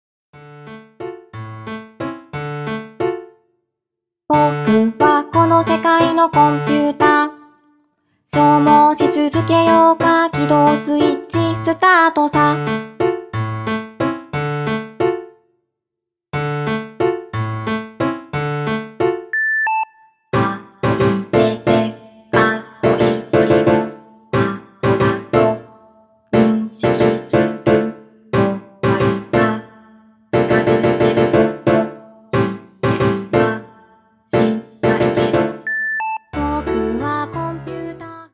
・全6曲幻想系ボーカルアルバム